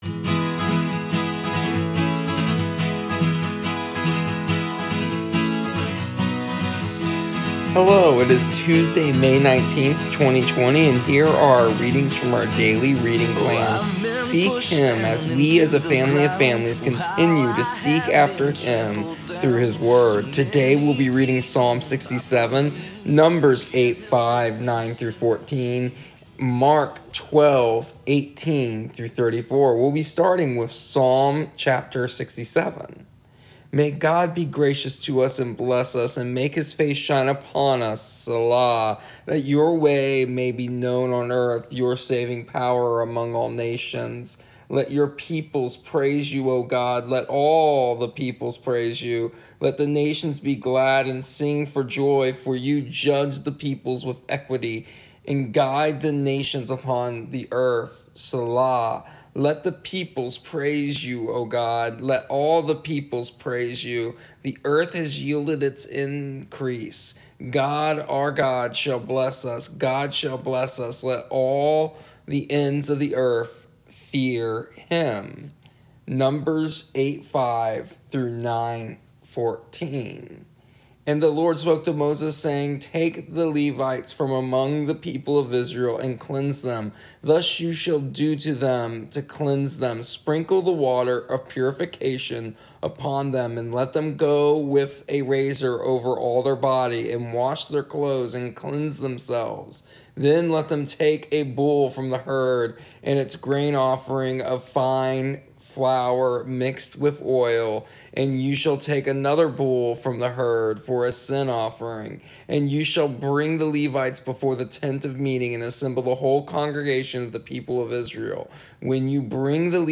Here are our readings in audio version for May 19th, 2020.